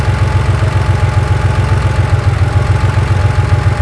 motor_a8.wav